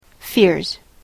/fɪrz(米国英語)/